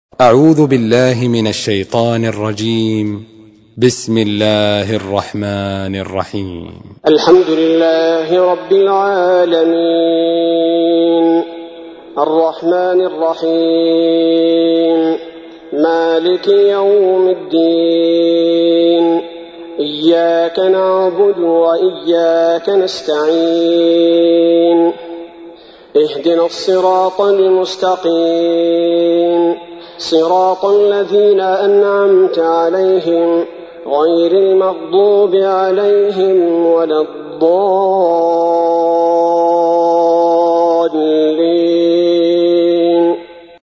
تحميل : 1. سورة الفاتحة / القارئ عبد البارئ الثبيتي / القرآن الكريم / موقع يا حسين